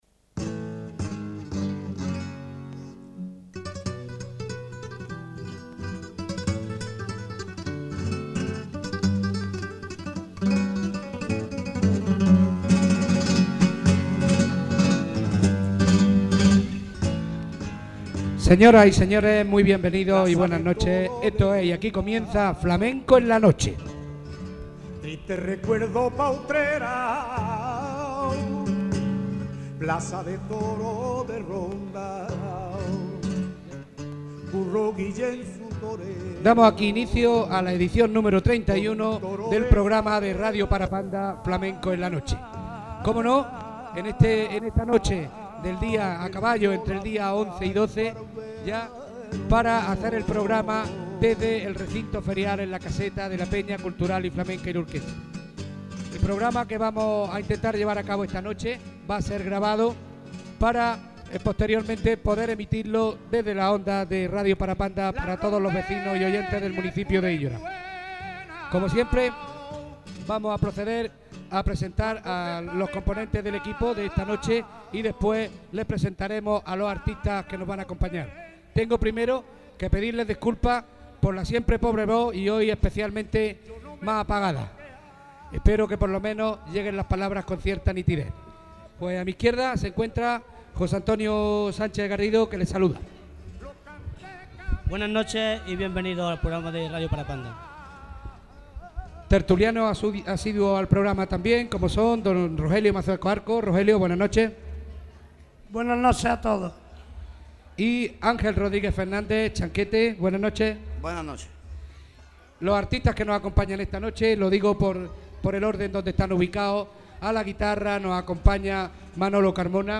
Archivo Sonoro - Resúmen - Flamenco en la Feria